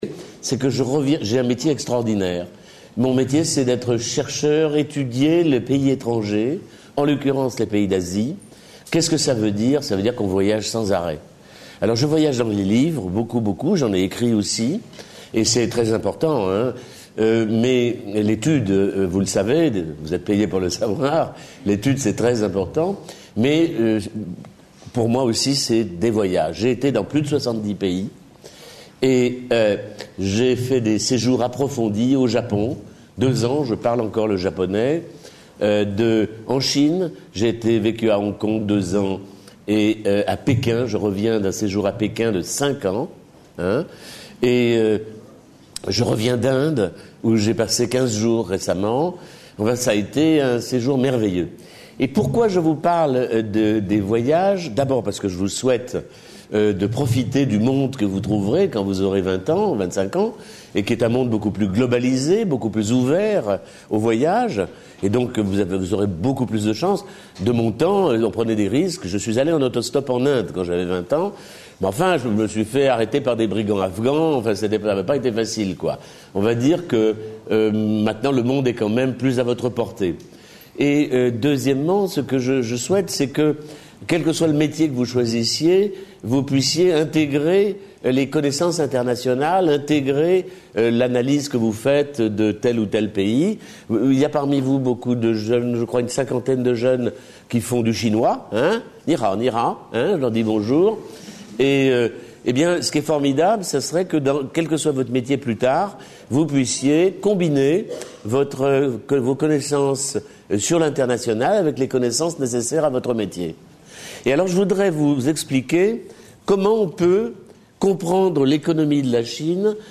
Une conférence de l'UTLS au Lycée L’économie chinoise aujourd’hui : Faut-il en avoir peur ?
LYCEE GALILEE (95 CERGY) Partenariat Région Ile de France